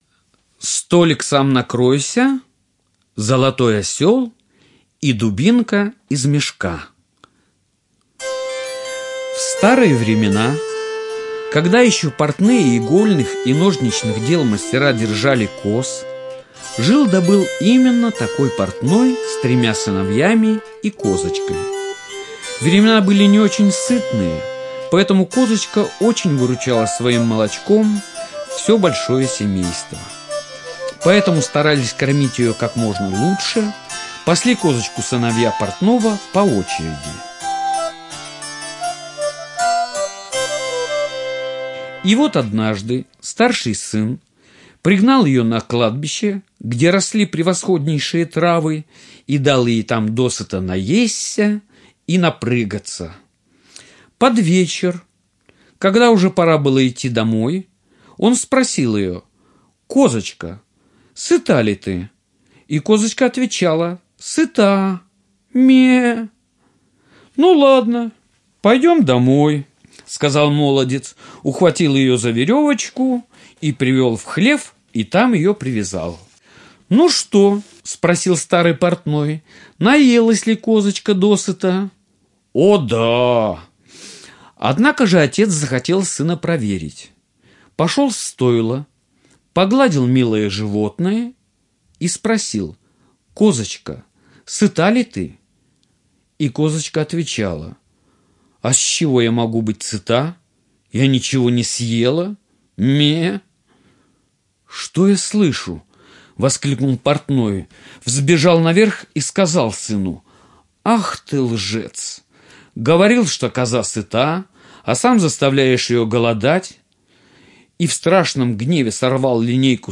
Слушайте онлайн сказку Столик, сам накройся, золотой осёл и дубинка из мешка - аудиосказка братьев Гримм. Сказка про трех сыновей портного.